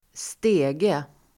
Ladda ner uttalet
Uttal: [²st'e:ge]